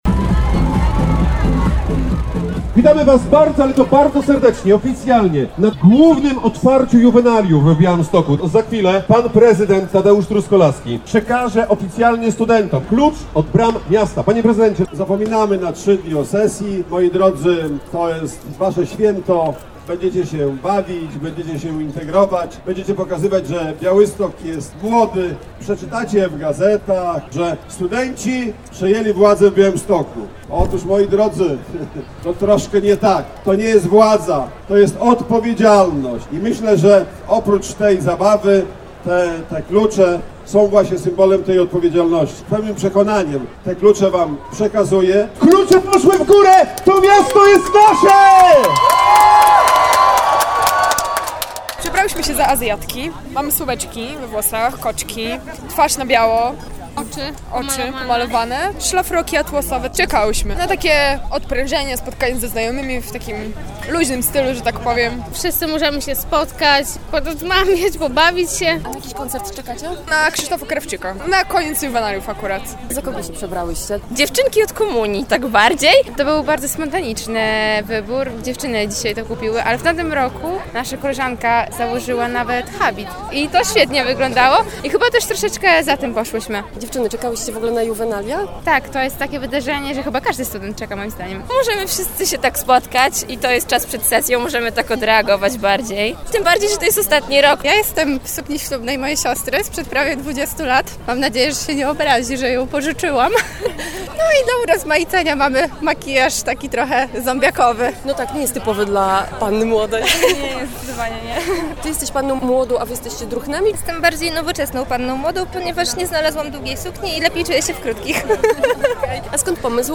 – Moi drodzy, to jest Wasze święto! Będziecie się bawić, będziecie się integrować, będziecie pokazywać, że Białystok jest młody. Z pełnym przekonaniem te klucze Wam przekazuję – mówił studentom Tadeusz Truskolaski, prezydent miasta.
30-parada-studentów.mp3